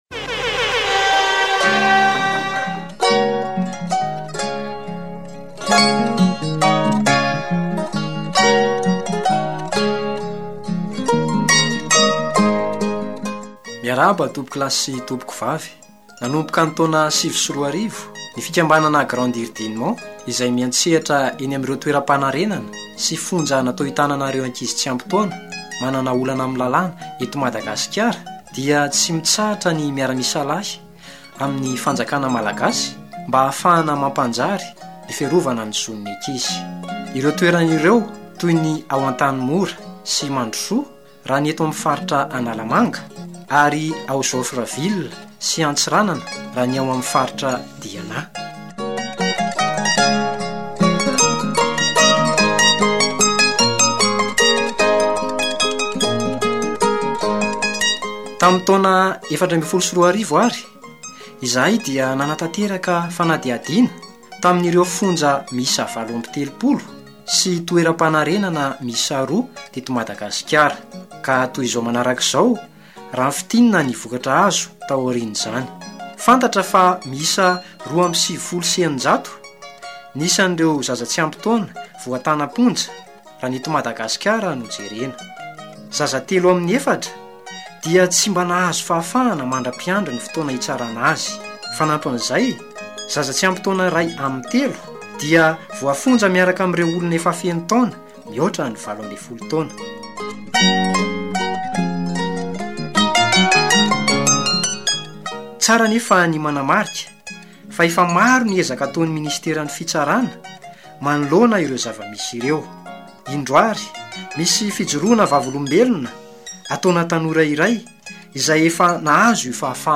Spot radio
Spot radio version française